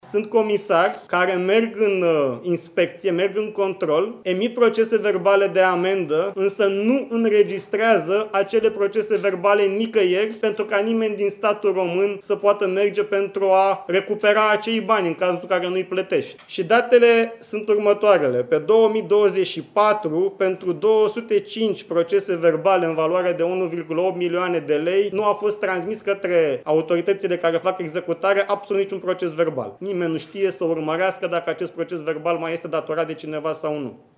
Ministrul Economiei trimite Corpul de Control la ANPC, după ce comisarii au dat amenzi, dar nu le-au înregistrat în sistem. În total, statul român a pierdut aproape 16 milioane de lei după ce amenzile au rămas neplătite, a anunțat, la o conferință de presă, ministrul Radu Miruță.